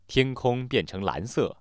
neutral